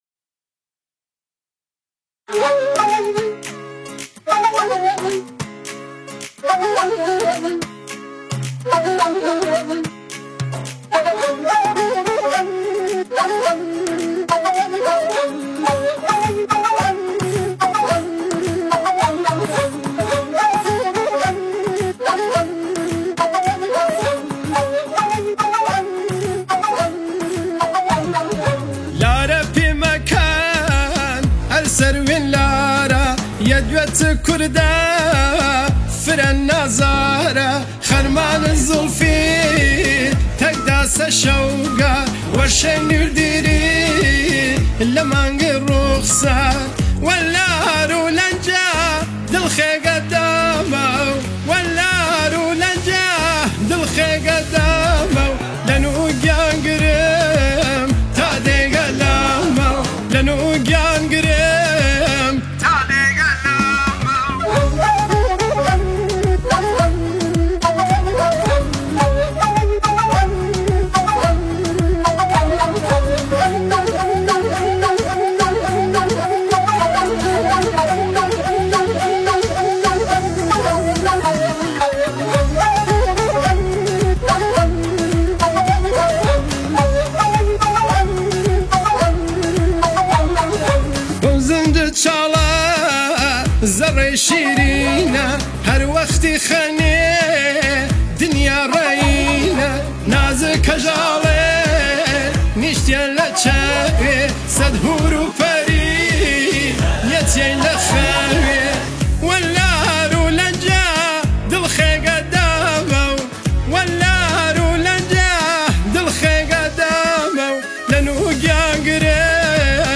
آهنگ کردی و سنندجی